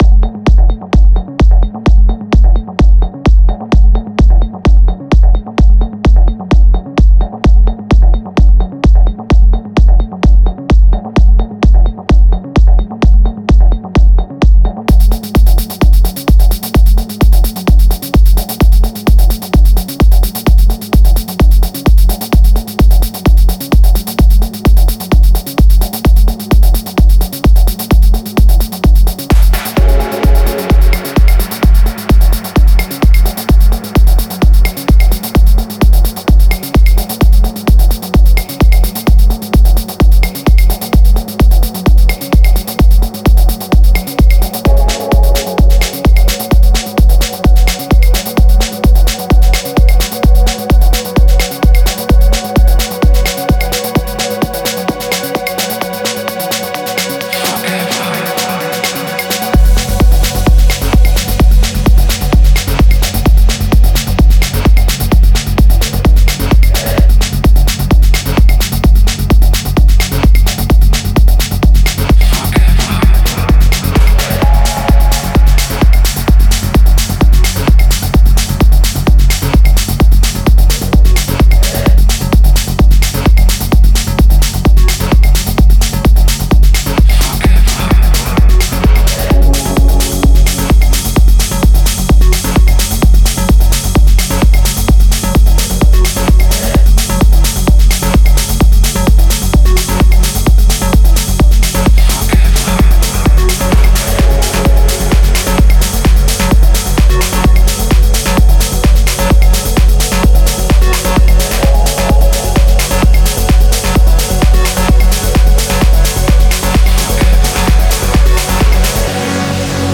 Теперь быстрее, колбаснее и немного трансовее.